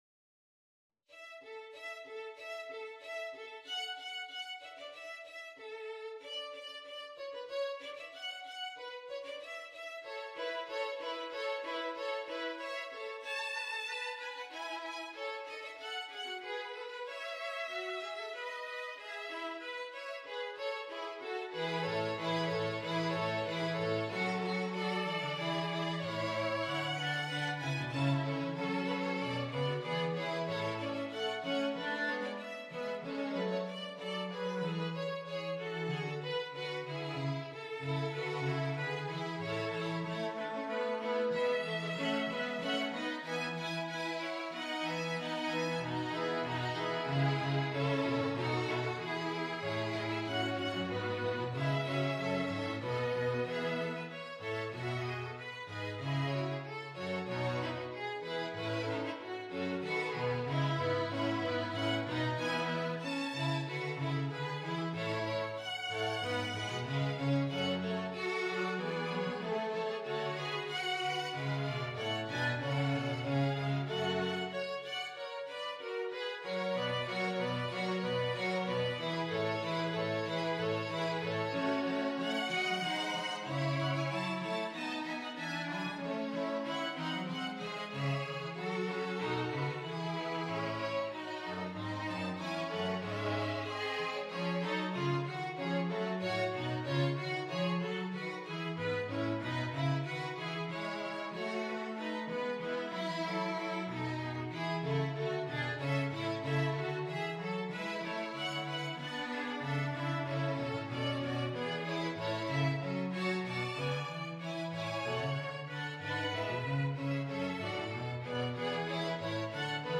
Free Sheet music for String Quartet
Violin 1Violin 2ViolaCello
Important composer from the Baroque period who became a British citizen in 1726.
4/4 (View more 4/4 Music)
A major (Sounding Pitch) (View more A major Music for String Quartet )
ÊÊAllegretto = c.94
Classical (View more Classical String Quartet Music)